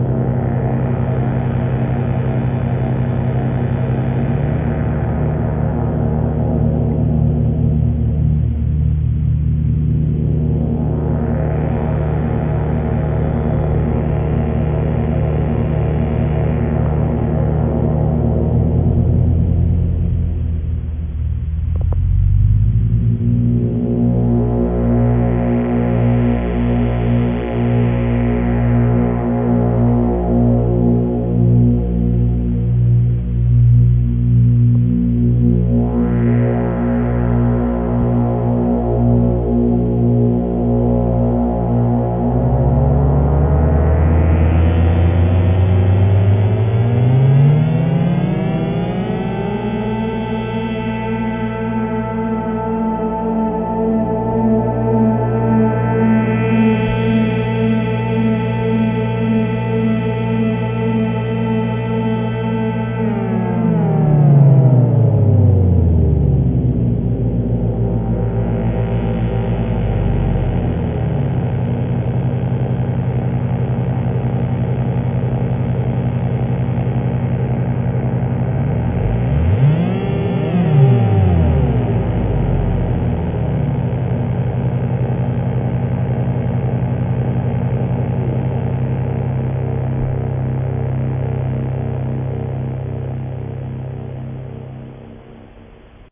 There’s a beat here but only 2 tracks total per pattern. Delay and Pitch sweeps on the OT crossfader with some comb filter later on.
Drone comes in on the 3rd pattern. 65bpm so give it some time.
Dirty and gritty and slow: